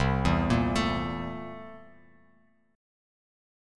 C13 Chord
Listen to C13 strummed